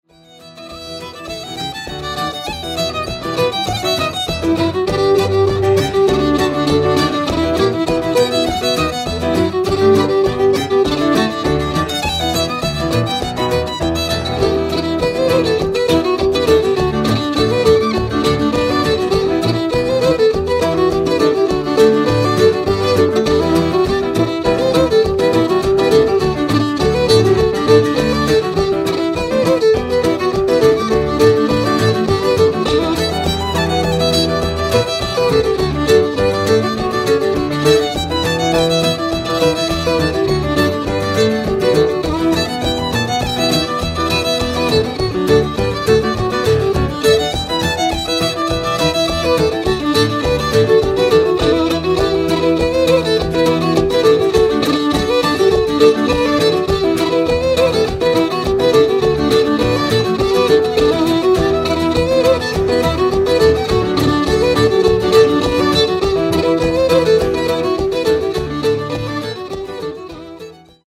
fiddle music